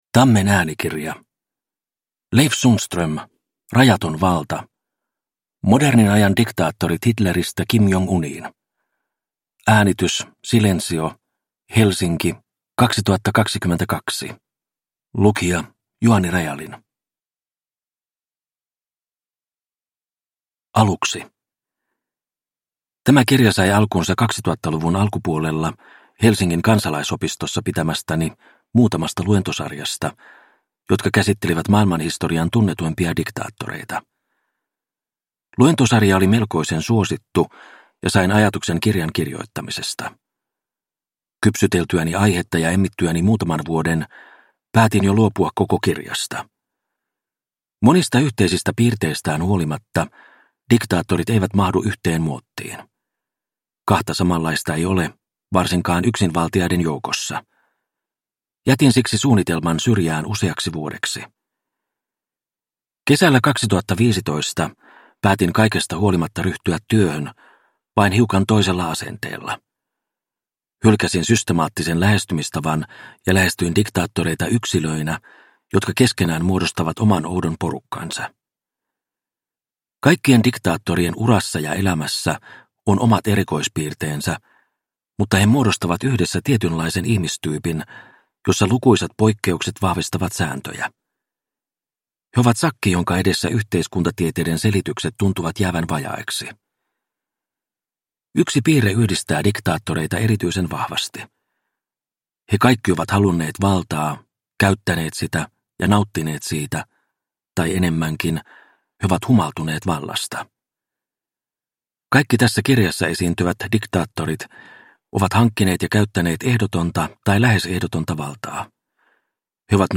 Rajaton valta – Ljudbok – Laddas ner